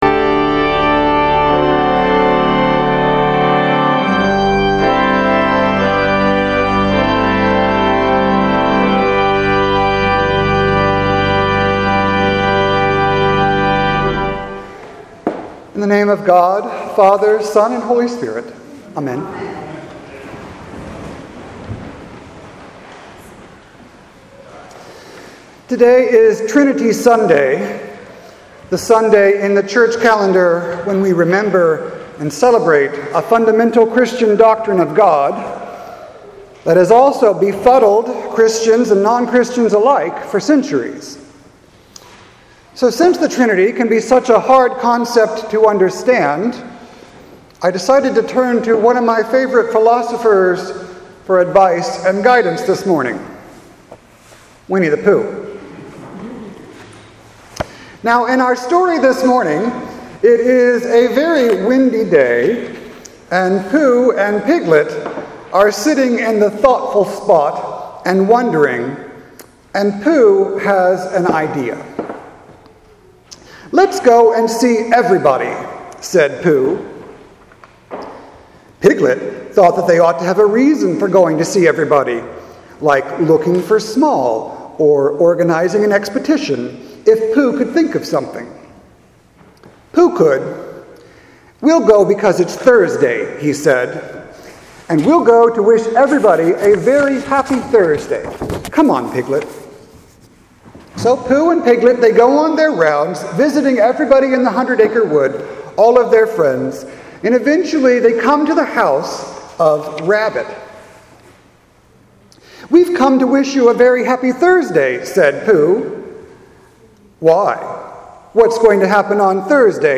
sermon5-27-18.mp3